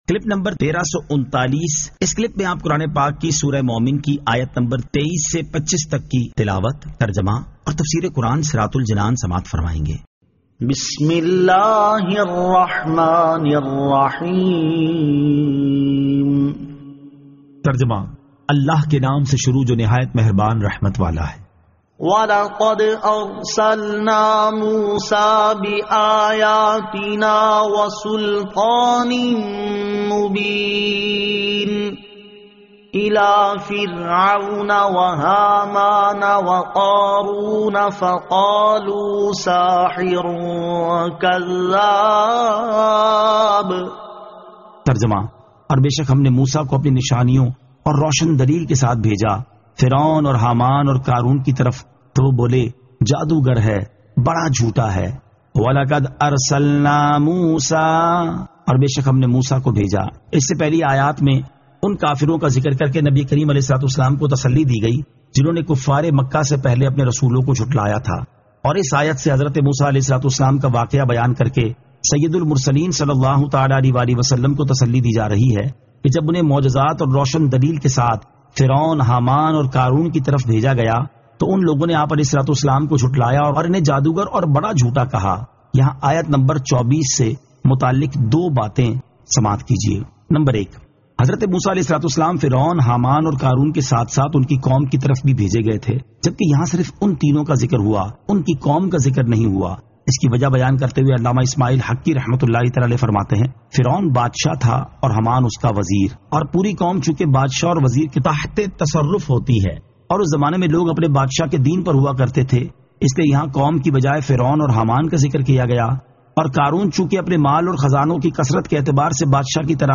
Surah Al-Mu'min 23 To 25 Tilawat , Tarjama , Tafseer